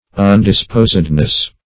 Undisposedness \Un`dis*pos"ed*ness\, n. Indisposition; disinclination.
undisposedness.mp3